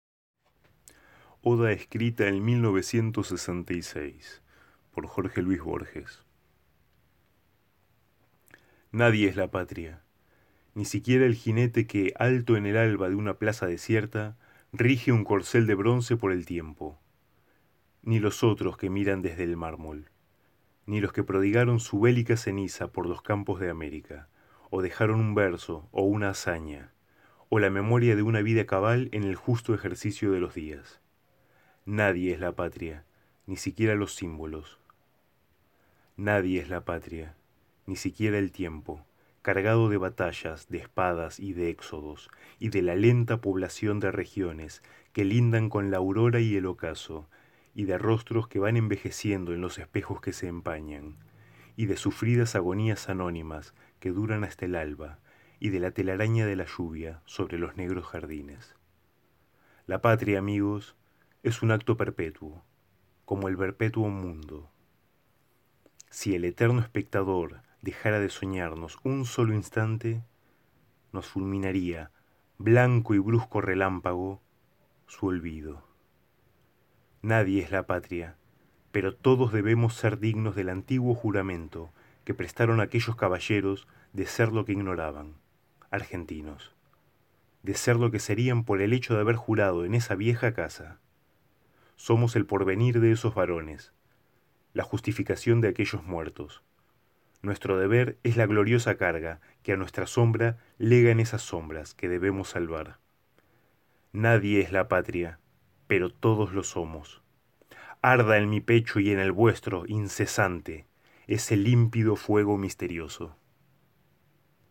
actor y amigo de «Te leo